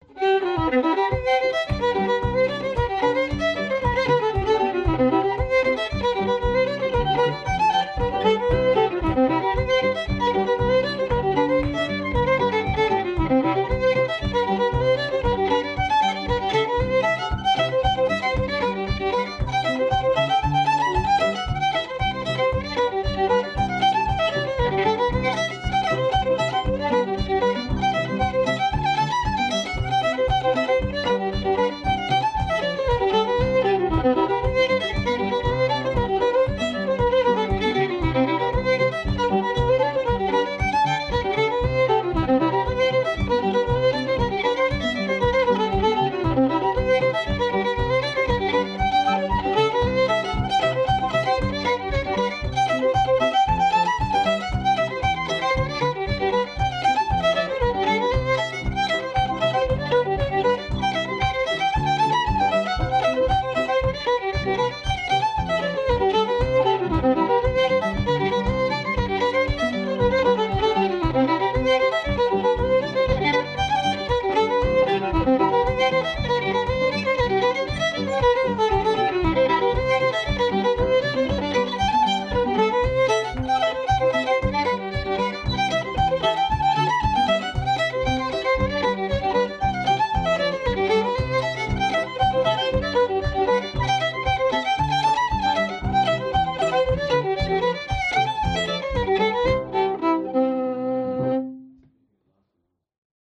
Reel à Joe Bibienne